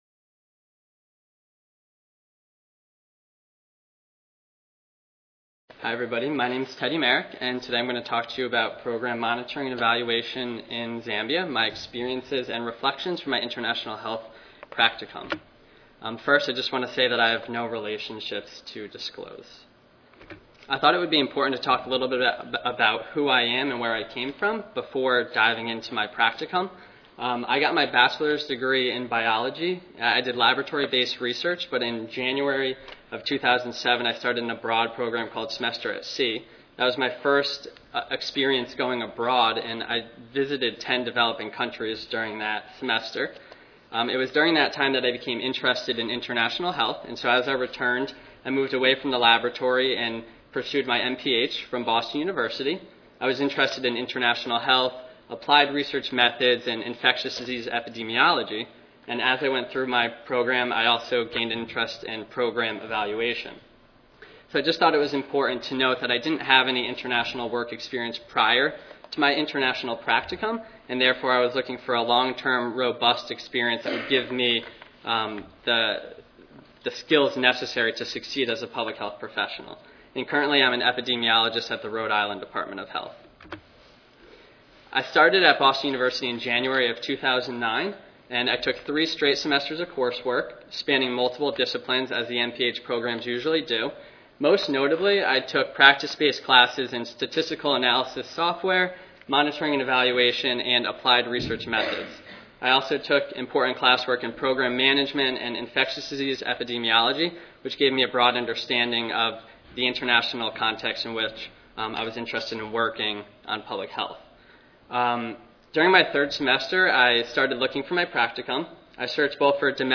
The panel of current students and recent graduates will share their international practicum experiences and discuss the importance of their work.